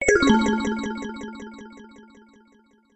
鉄琴の一種のヴィブラフォン音色。
メール音やSMSの通知音。シンセサイザーでの音程が下降していくエフェクト。